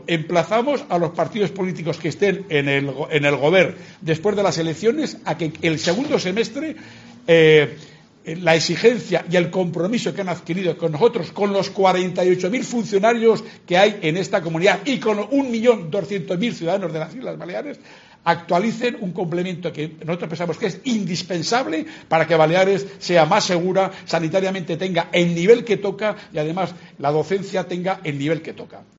Corte del Informativo Mediodía